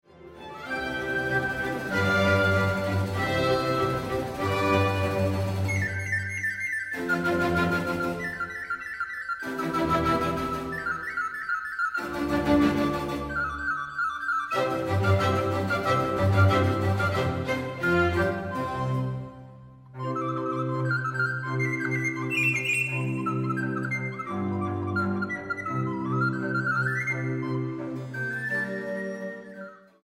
para flauta sopranino, cuerdas y contínuo